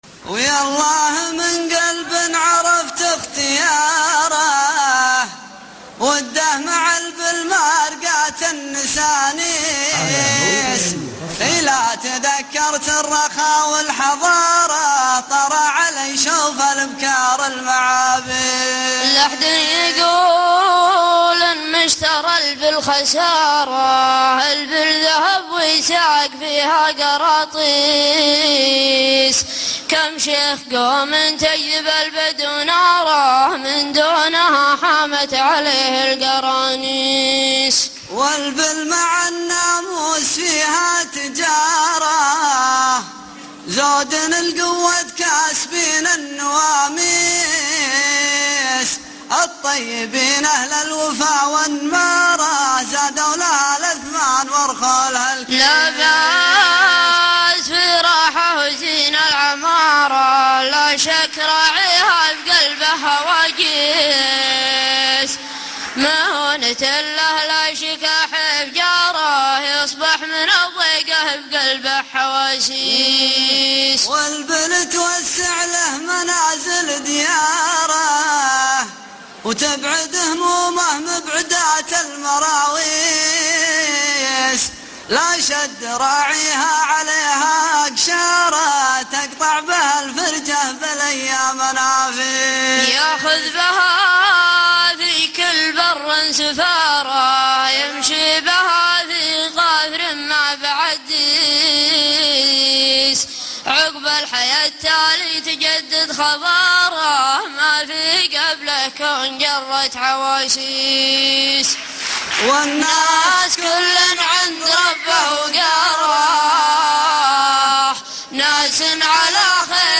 دويتو
في حفل الختامي